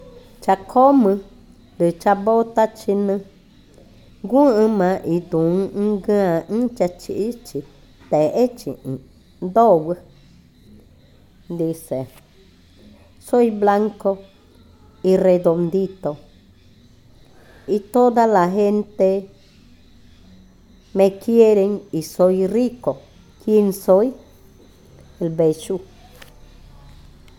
Adivinanza 22. El beijú
Cushillococha